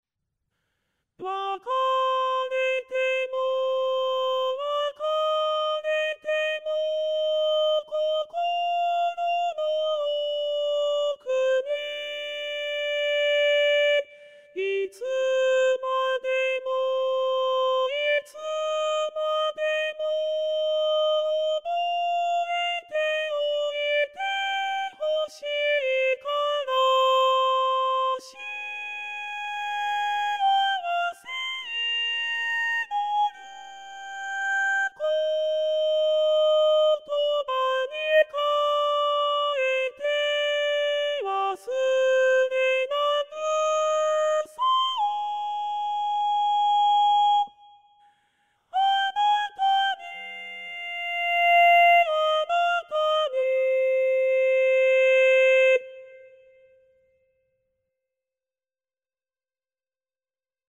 歌詞を演奏していて一番困ったことが、2分音符や全音符などの長音符が不自然にクレッシェンドされてしまうことです。
これを、下のようにビブラートをゼロにします。(左端にします)
やはり歌い放つ状況は同じです。味気ない演奏です。